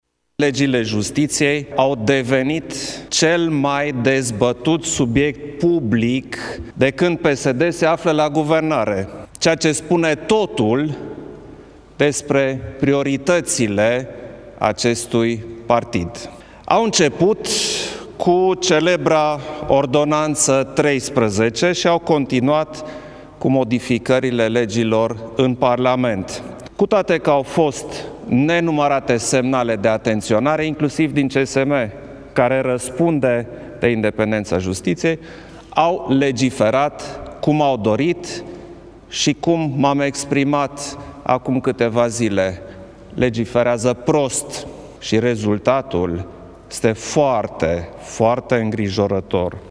Legile trebuie revizuite urgent în Parlament, în sesiunea din toamnă, a mai declarat președintele la Palatul Cotroceni.
declaratii-Iohannis.mp3